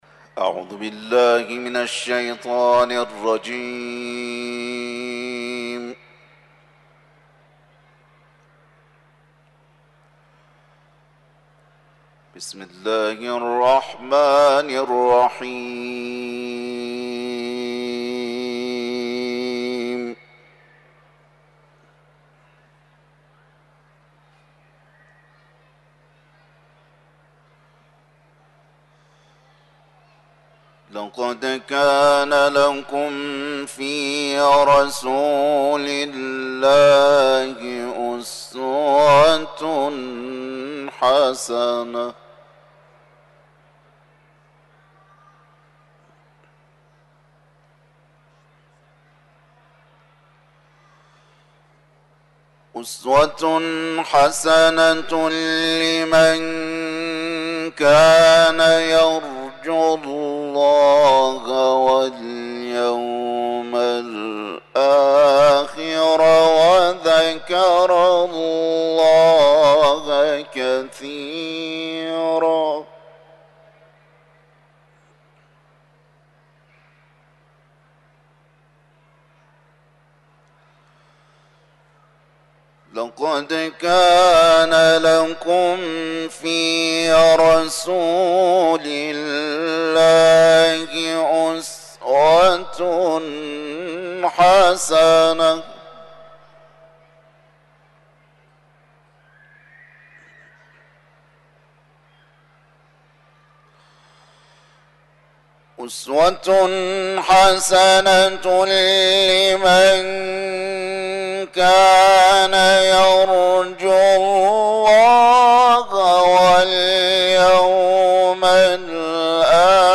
صوت تلاوت آیاتی از سوره‌ «احزاب»